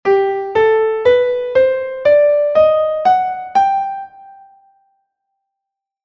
Escala Maior